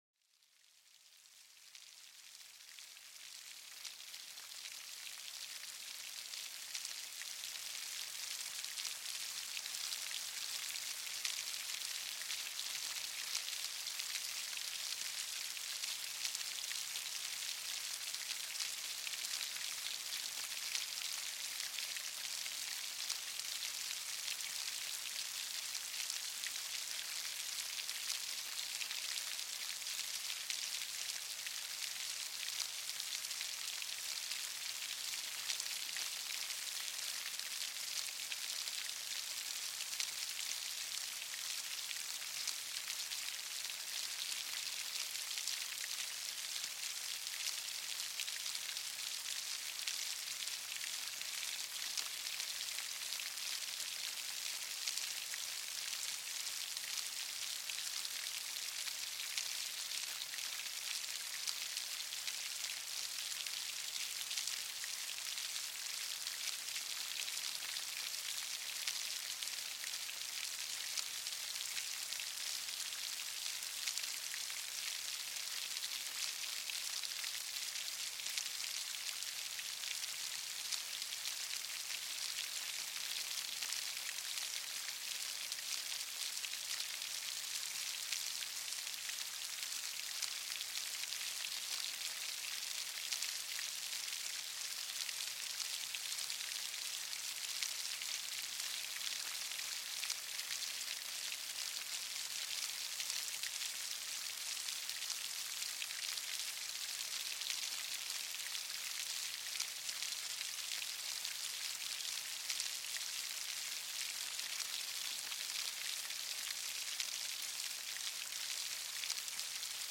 Pluie Apaisante : Les Grosses Gouttes qui Lavent l'Esprit